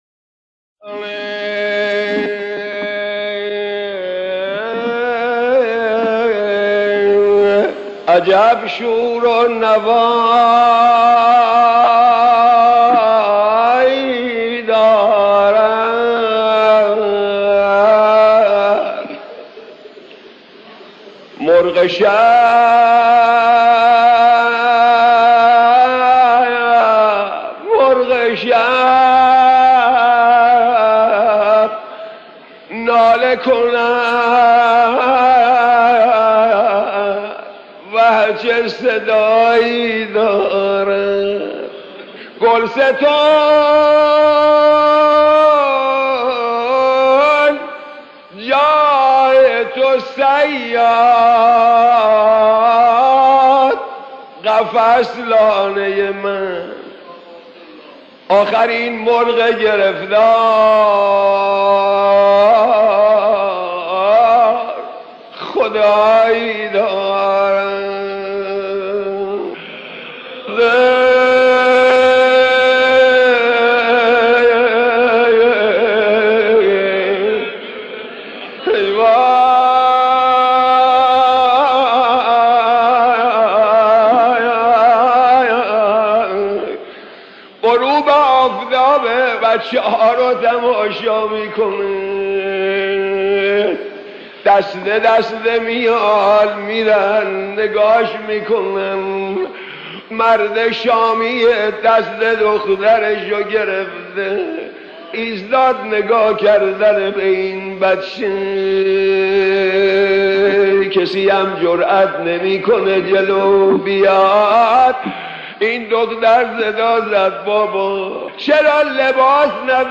مصیبت‌خوانی در وصف تنهایی حضرت رقیه(س)